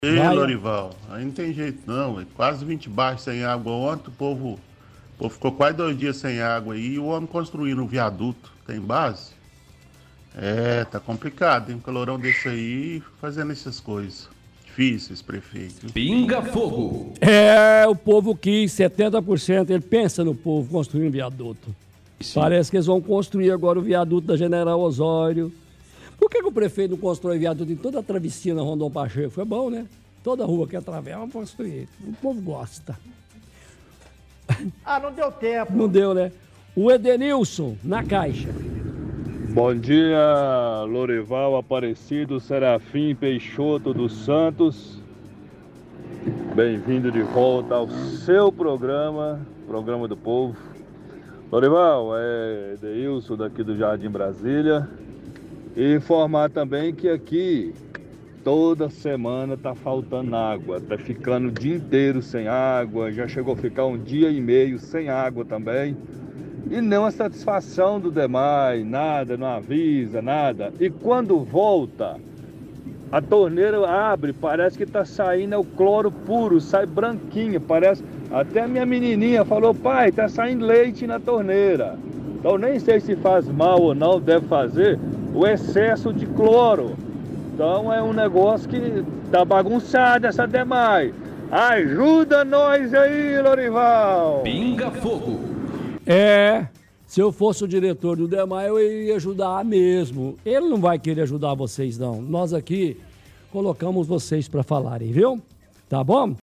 – Ouvinte também reclama da falta de água, comenta: “Quase 20 bairros faltando água e esse homem querendo construir viaduto.”